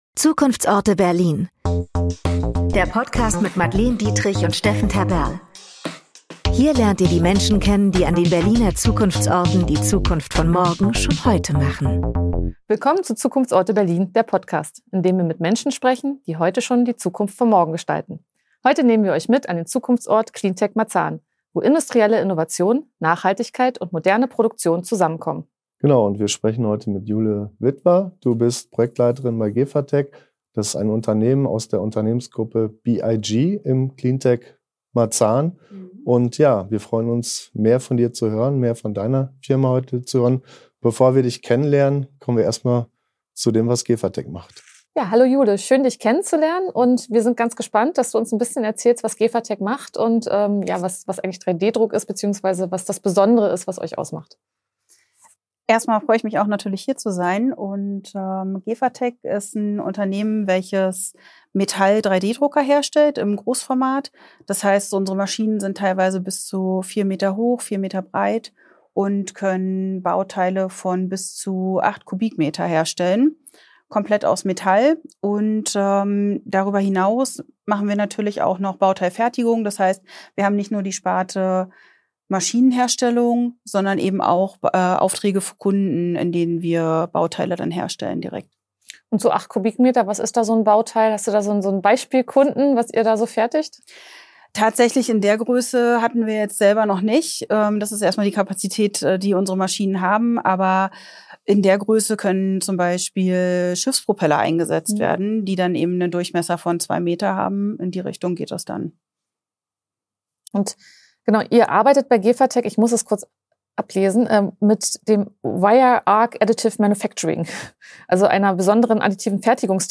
Im Gespräch geht es um additive Fertigungstechnologien, besonders großformatige Metall-3D-Druckverfahren, die ressourceneffiziente Produktion unterstützen.